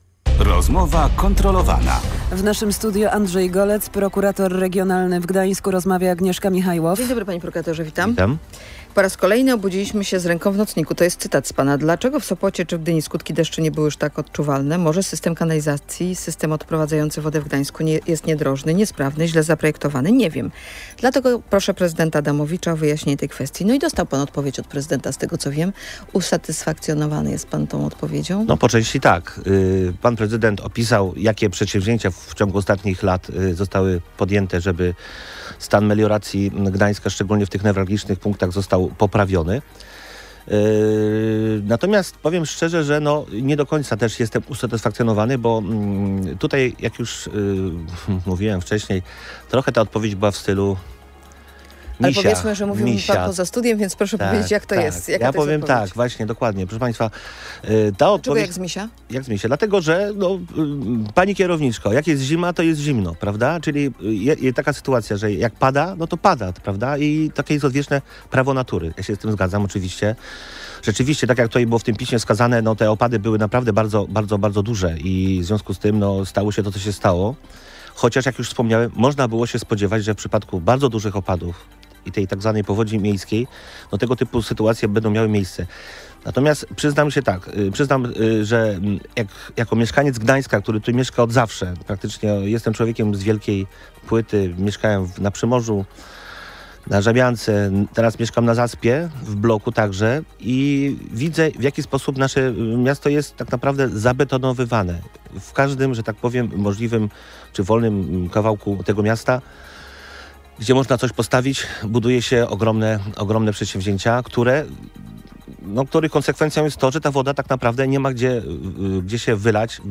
Płatne parkingi w Brzeźnie są nielegalne. Odcinek ulicy Hallera to droga publiczna, a zarządza nim prywatna firma. Być może wystąpię z powództwem – tak w Radiu Gdańsk sprawę parkingów w Gdańsku Brzeźnie ocenił szef Prokuratury Regionalnej w Gdańsku Andrzej Golec.